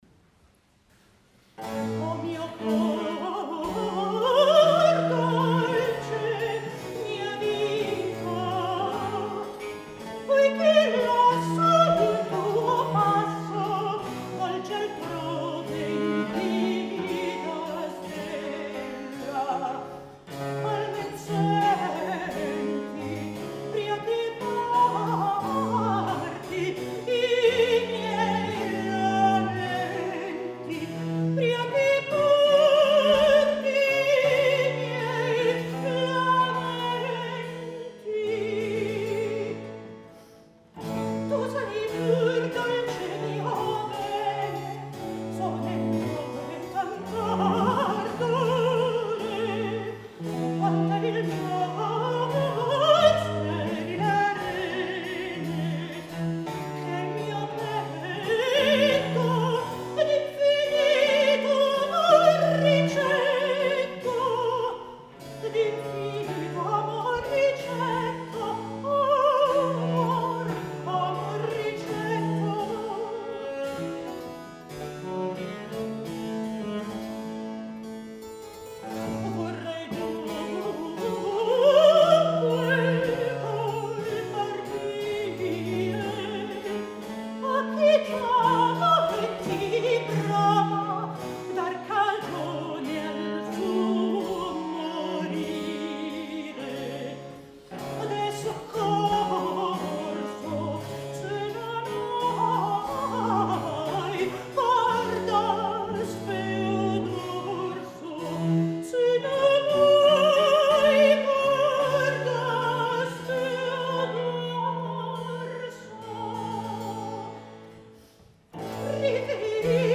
Venue: St. Brendan’s Church
Instrumentation Category:Small Mixed Ensemble
Instrumentation Other: Mez-solo, vc, lu, hpd
mezzo-soprano
recorder/cello
theorbo/archlute
harpsichord
strophic melody, O